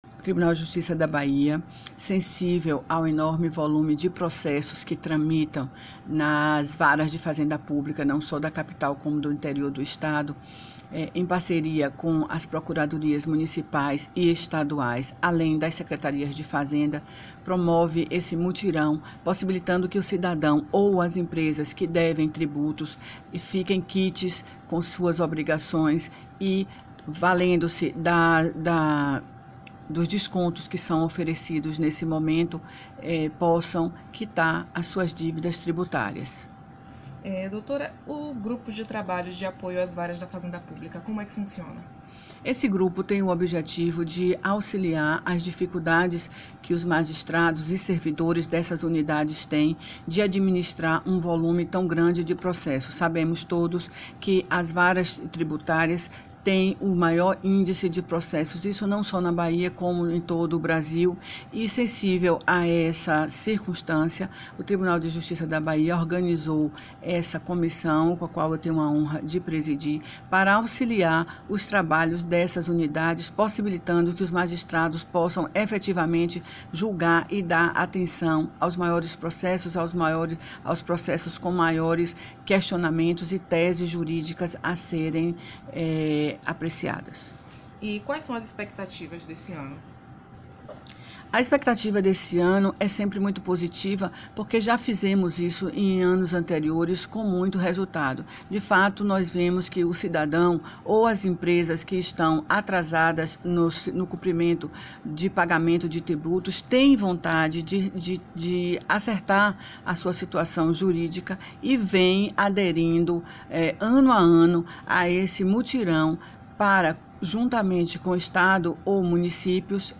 Em entrevista à Assessoria de Comunicação do TJBA, a Presidente da Comissão, Desembargadora Maria de Lourdes Pinho Medauar, registrou a importância da campanha e destacou o objetivo da Comissão de Apoio às Varas da Fazenda Pública do TJBA. Além disso, salientou as expectativas para a Semana de Conciliação de débitos.